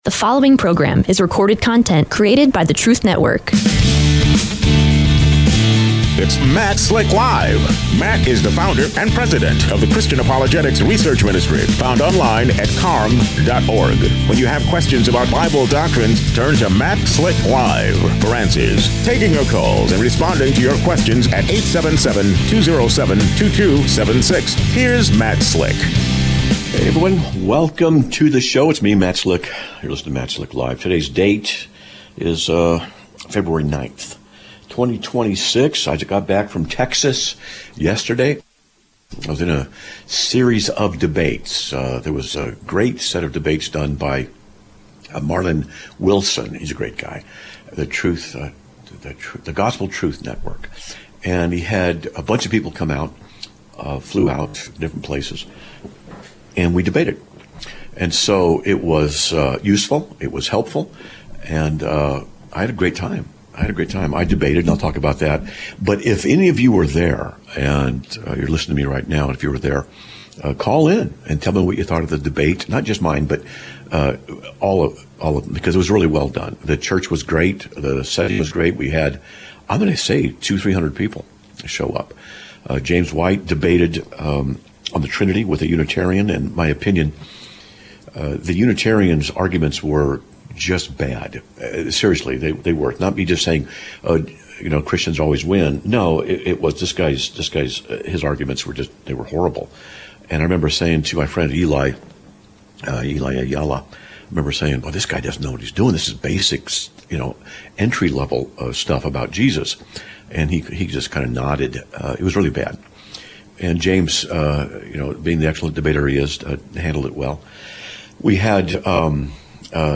Live Broadcast of 02/09/2026